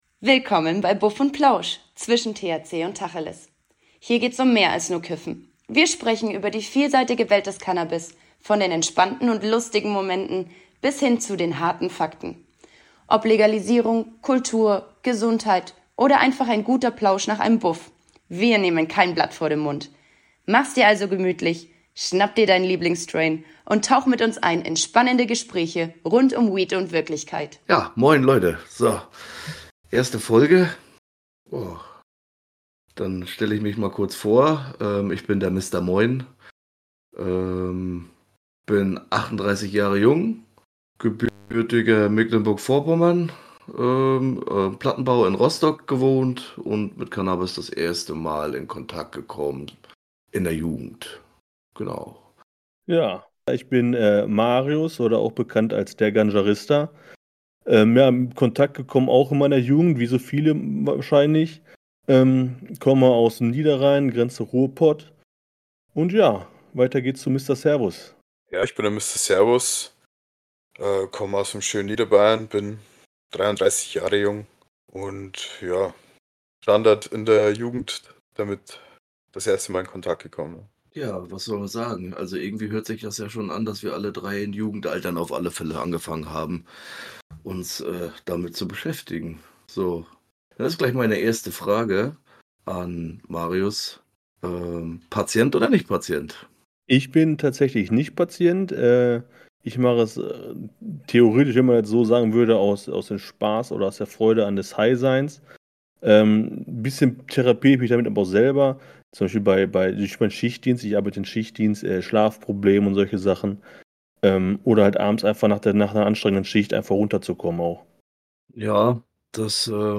Beschreibung vor 1 Jahr In unserer allerersten Folge stellen wir uns vor: Drei Jungs mit einer Leidenschaft für Cannabis. Anfangs sind wir noch angespannt, aber keine Sorge!